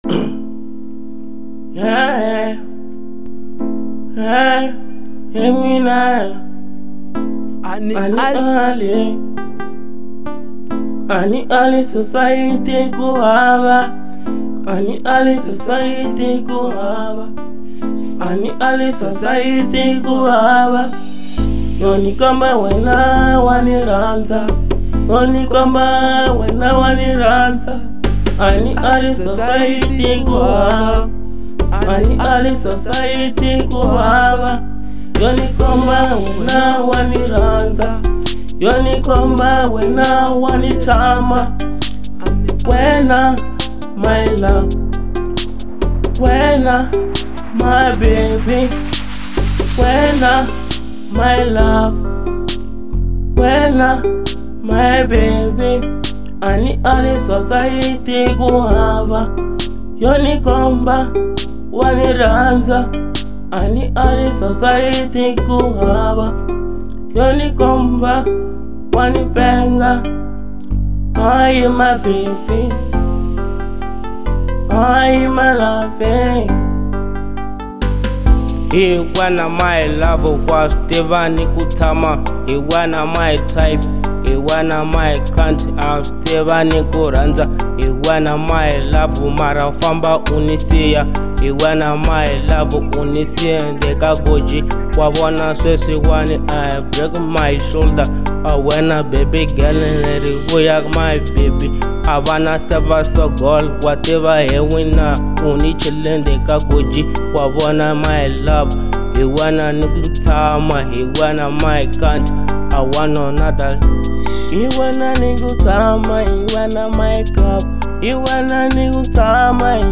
03:49 Genre : Trap Size